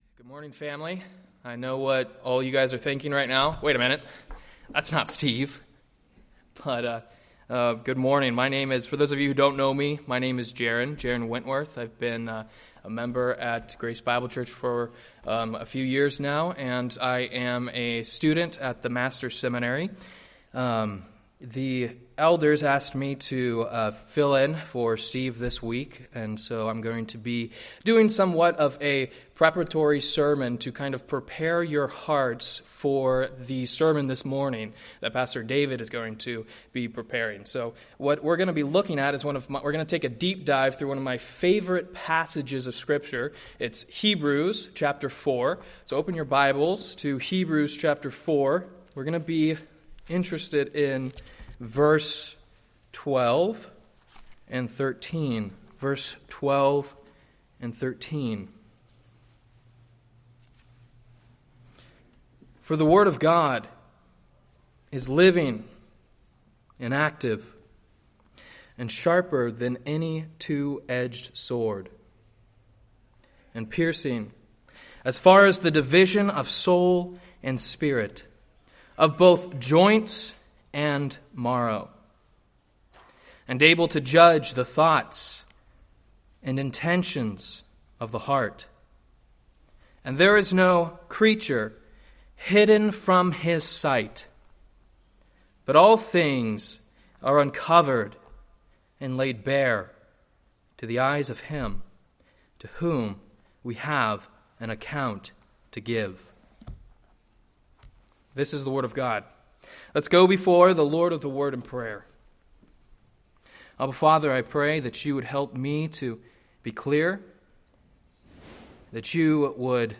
Date: Jun 2, 2024 Series: Various Sunday School Grouping: Sunday School (Adult) More: Download MP3 | YouTube